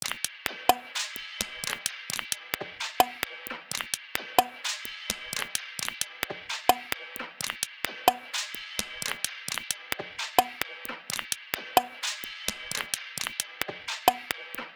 130BPM - AFTER DARK.wav